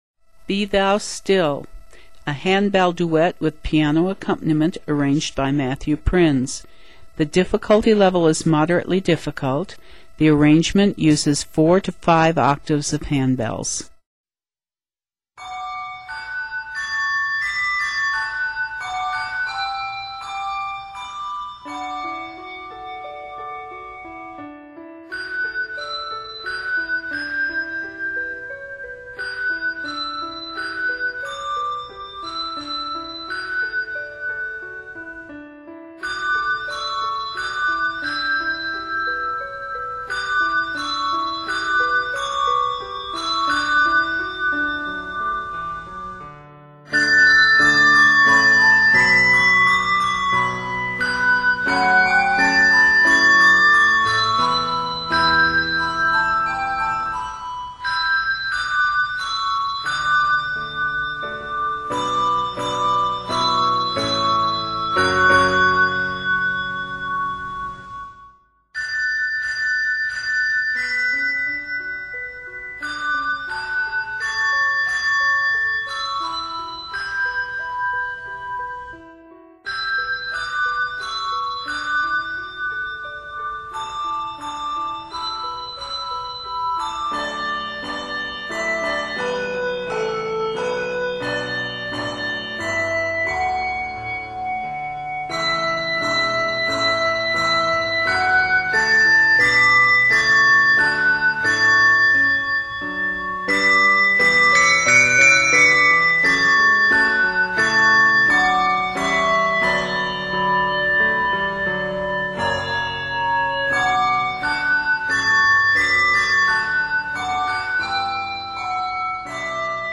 handbell duet
is arranged in D Major and A Major.
Hymn Tune , Medley , Bell Tree